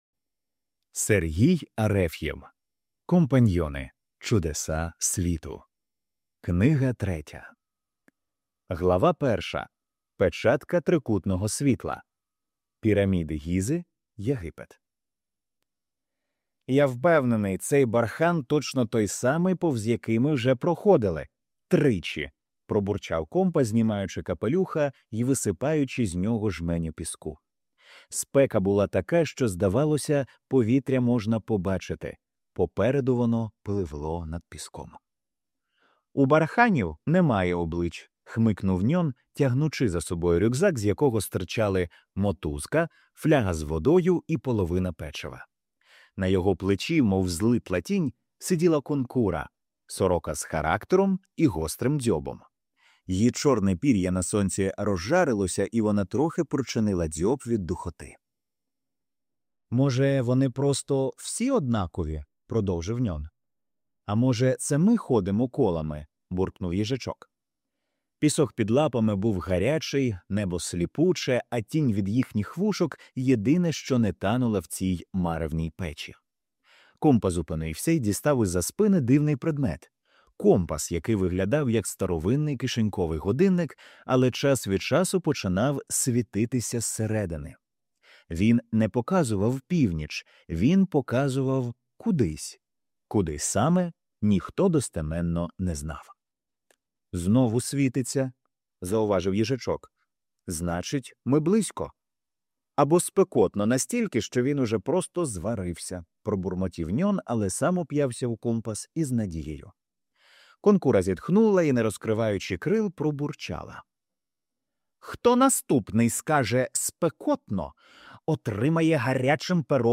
Аудіоказка Компаньйони та чудеса світу